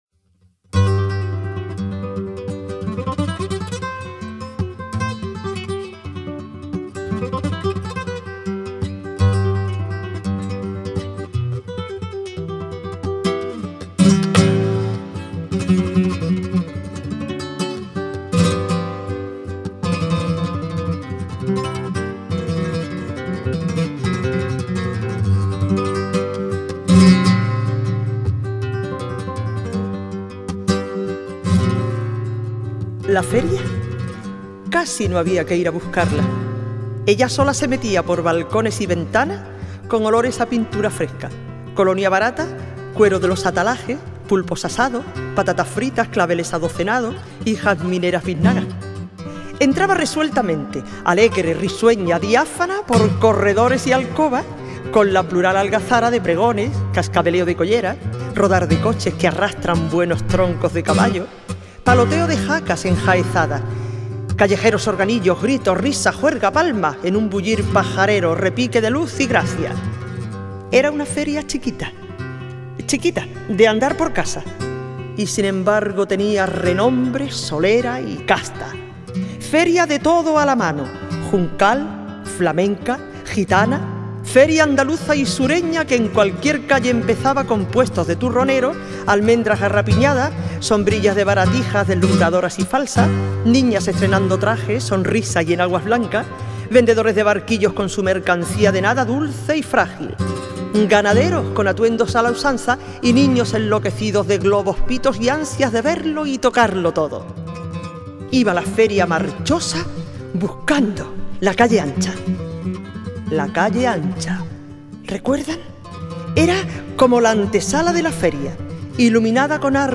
Poemas